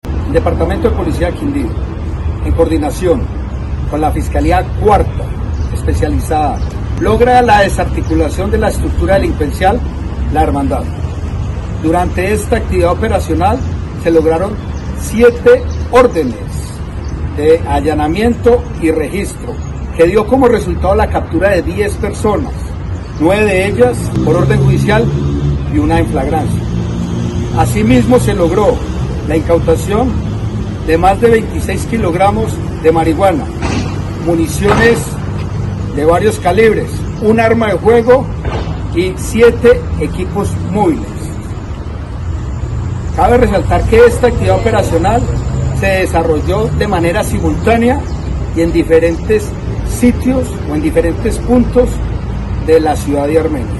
Coronel, Carlos Mario Bustamante, comandante Policía, Quindío